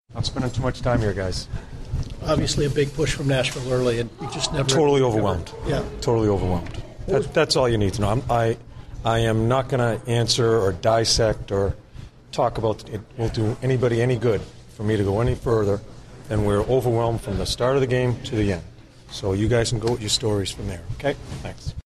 John Tortorella Post-Game 03/26/16